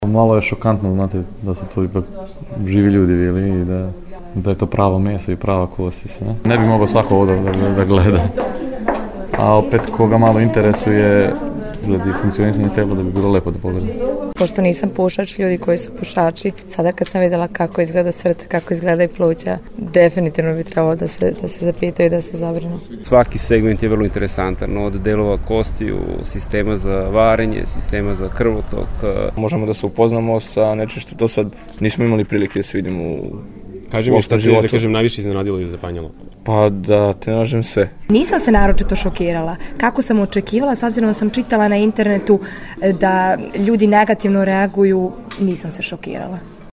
Posetioci o izložbi